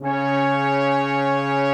BRASS 3C#4.wav